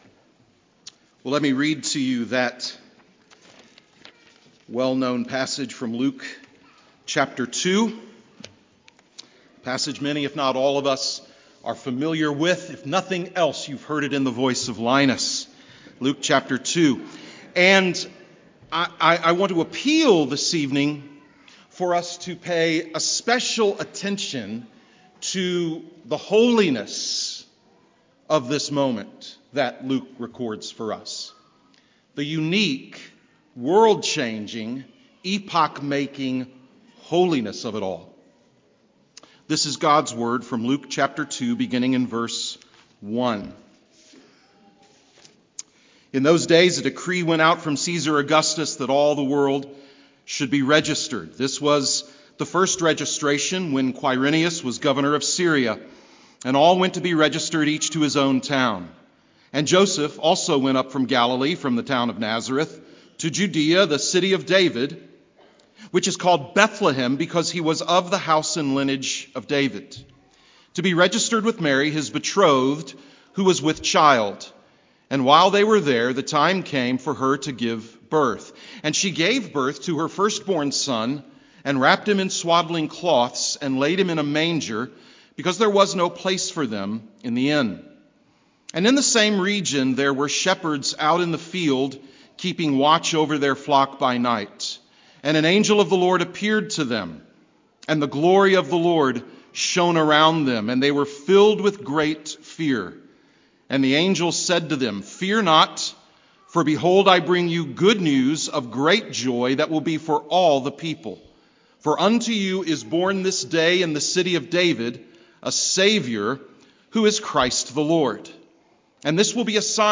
Christmas Eve Sermon 2024